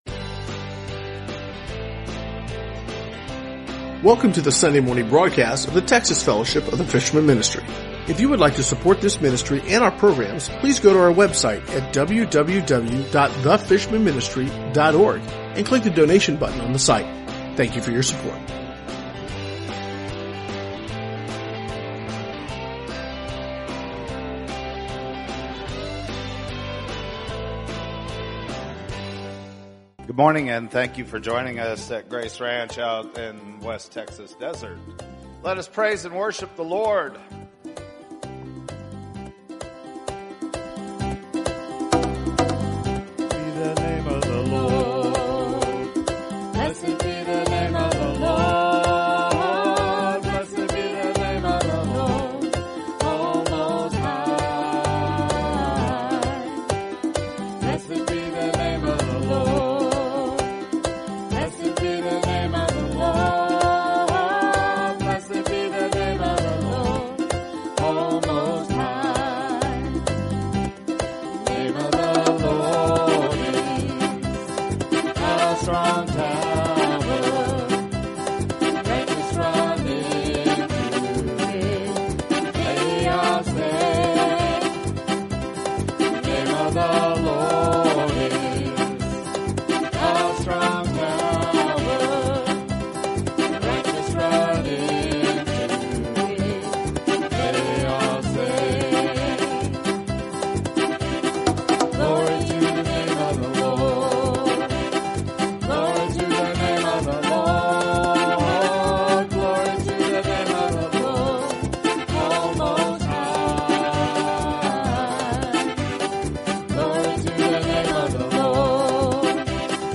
Sunday Texas Church Service 09/13/2015 | The Fishermen Ministry